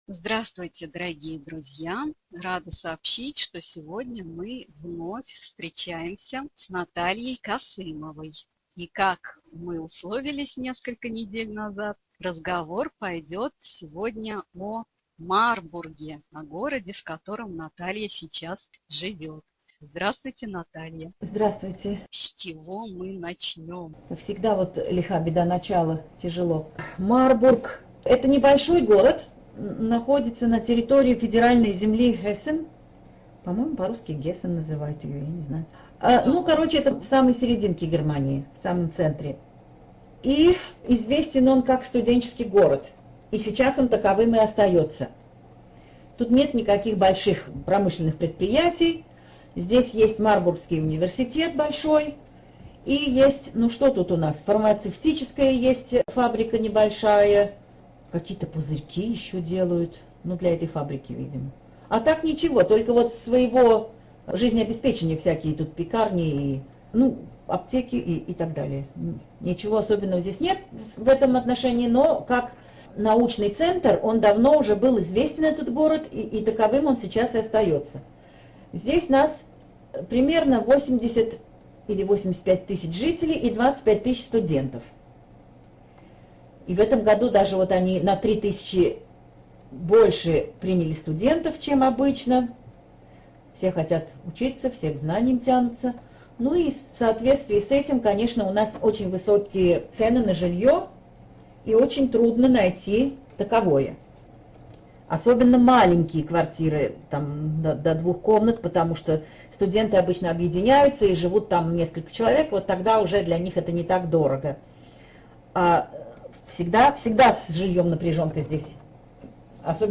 ЖанрАудиоэкскурсии и краеведение